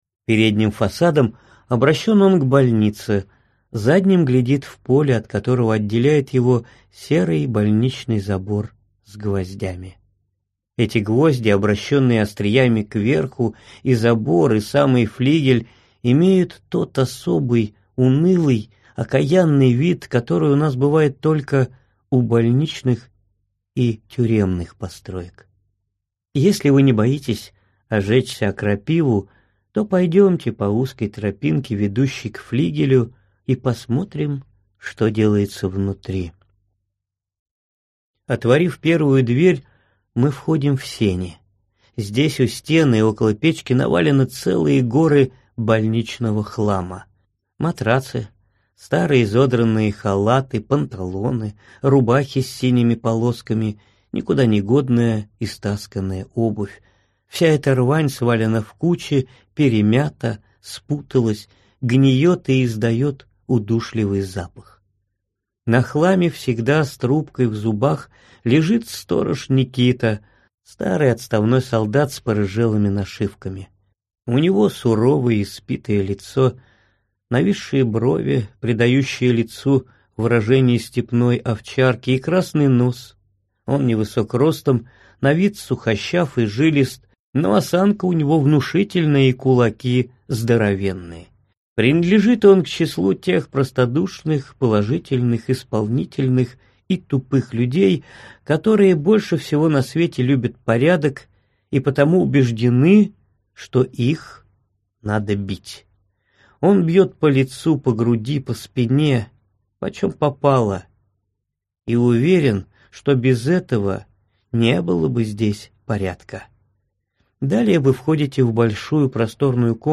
Аудиокнига Избранные повести и рассказы | Библиотека аудиокниг